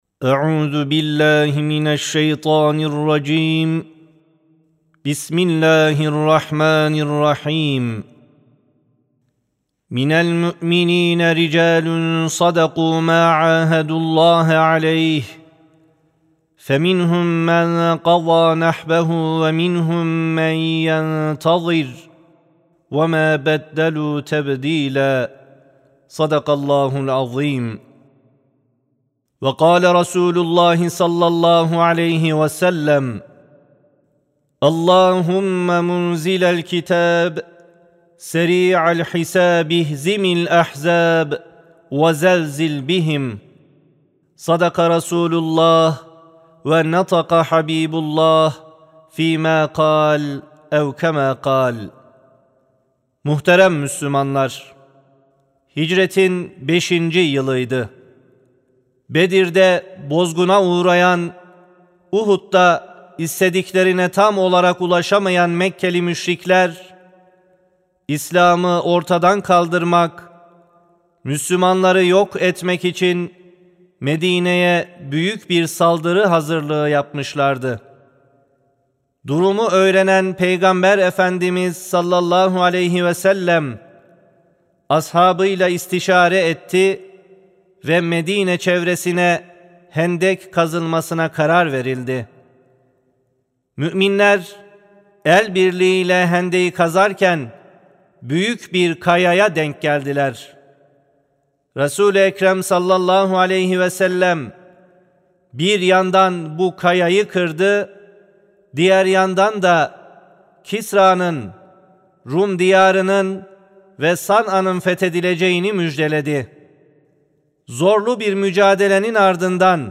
03 Ekim 2025 Tarihli Cuma Hutbesi
Sesli Hutbe (Hendek'ten Gazze'ye, Zafer İnananlarındır).mp3